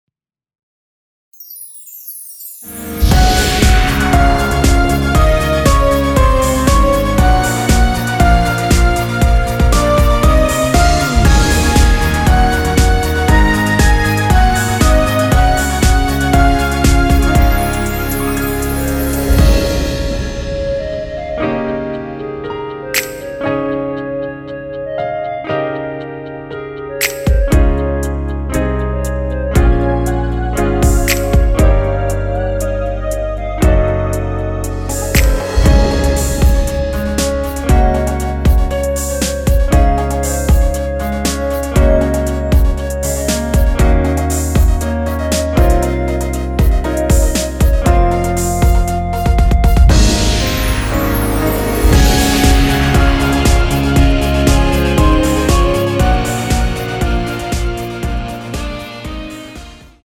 원키에서(-2)내린 멜로디 포함된 MR입니다.(미리듣기 확인)
Db
앞부분30초, 뒷부분30초씩 편집해서 올려 드리고 있습니다.
중간에 음이 끈어지고 다시 나오는 이유는